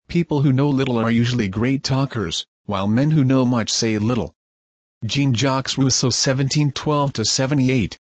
(Translate and Speak by Smart Link Corporation)